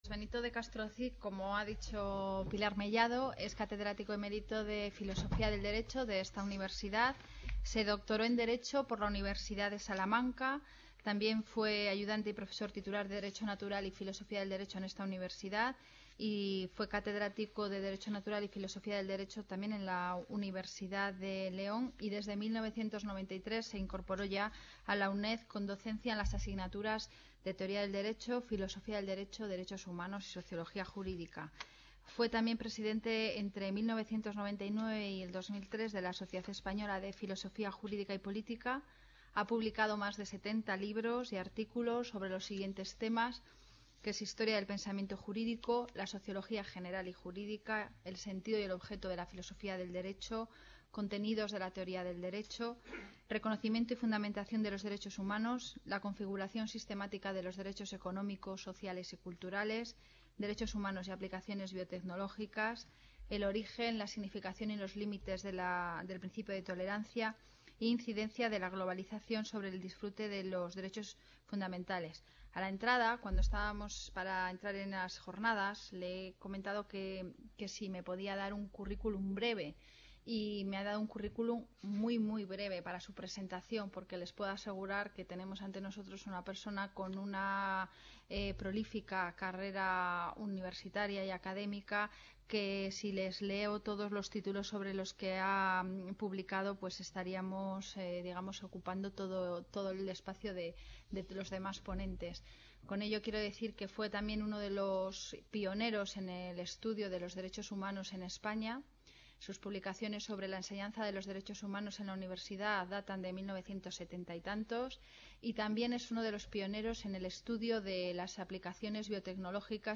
Reunion, debate, coloquio...